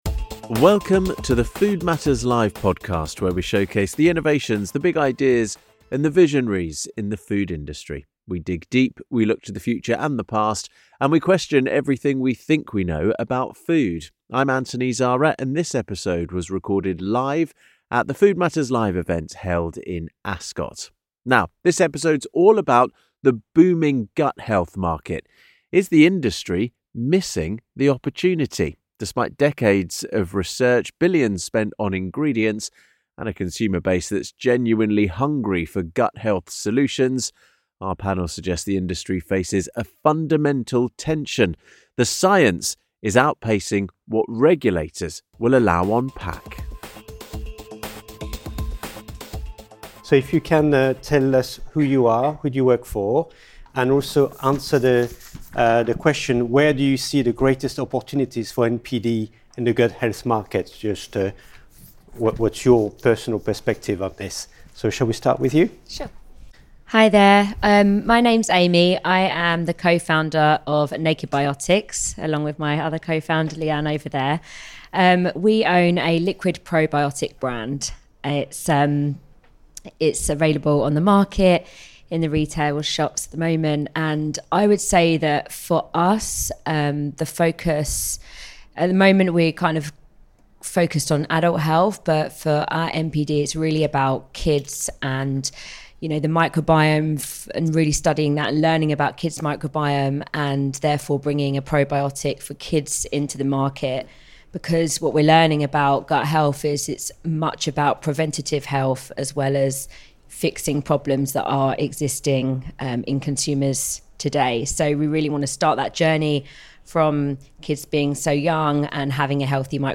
In this episode of the Food Matters Live podcast, recorded at our Ascot event, our panel of experts debate where the real opportunity lies in gut health NPD - and where the industry keeps tripping itself up.